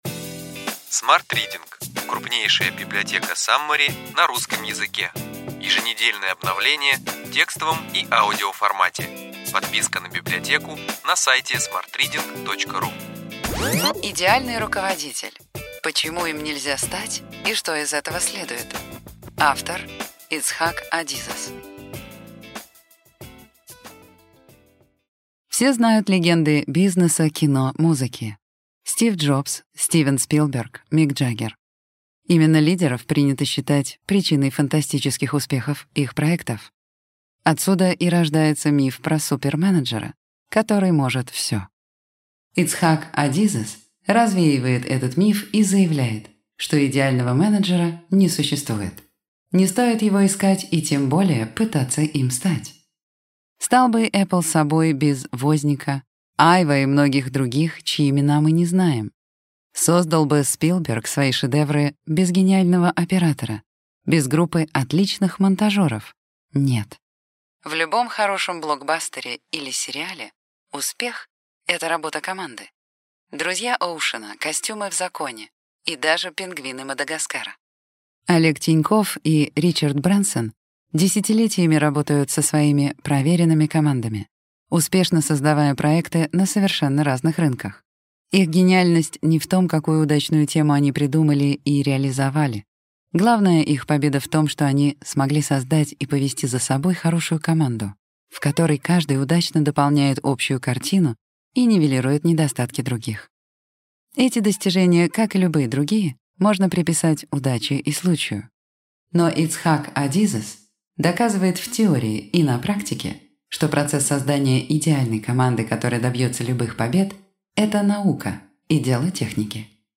Аудиокнига Ключевые идеи книги: Идеальный руководитель. Почему им нельзя стать и что из этого следует.